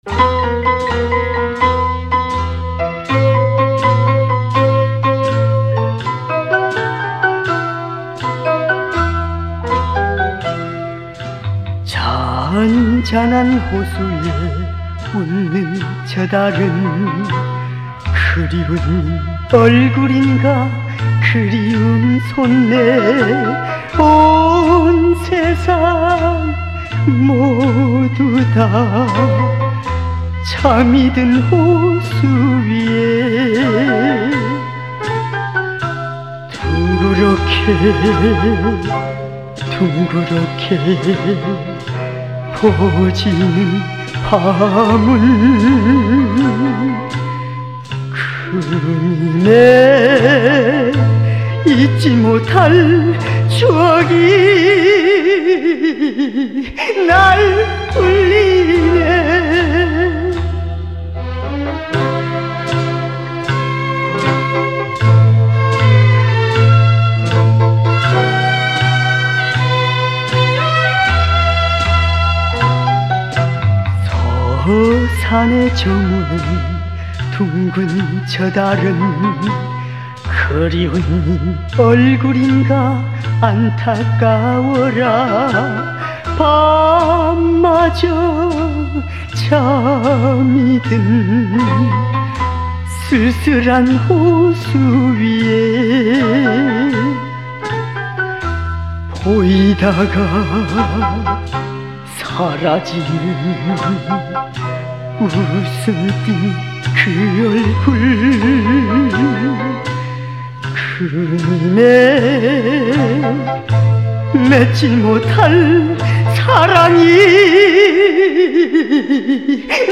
(Smart Phone)